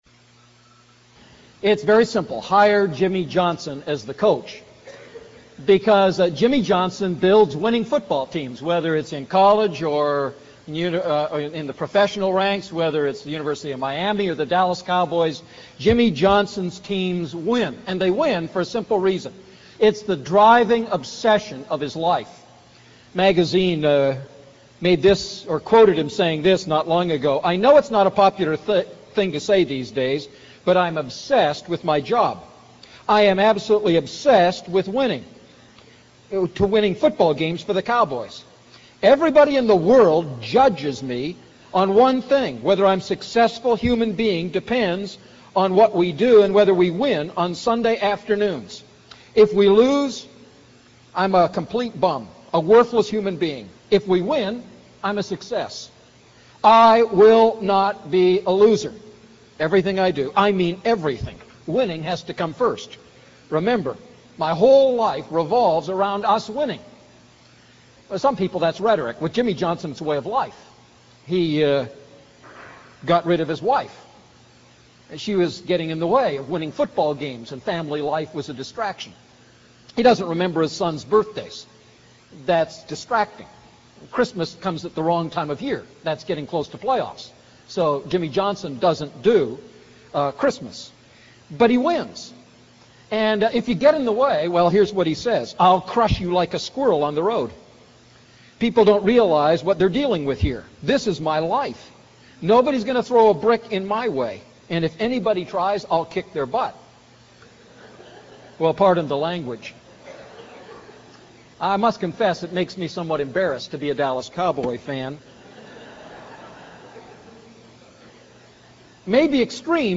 A message from the series "Luke Series I."